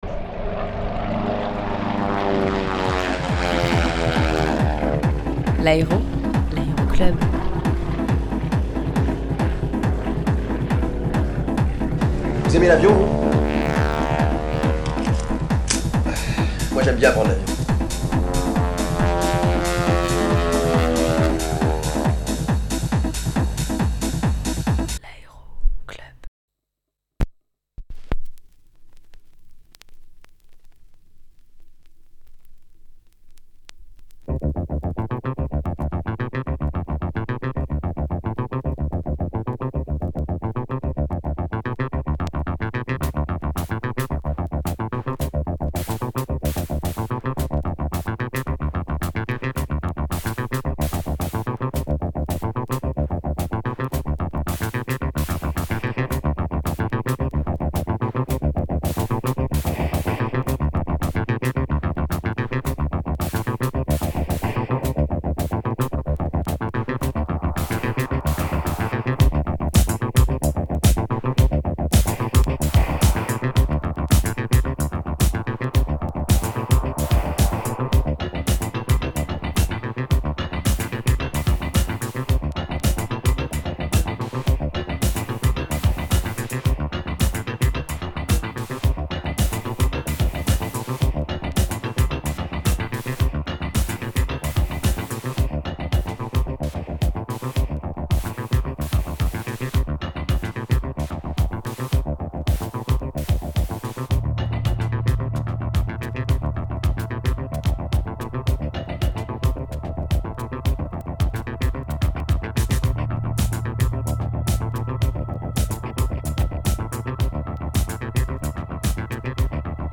Que du vinyle pour ce premier aéroclub !
Mix Électro